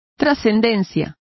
Complete with pronunciation of the translation of import.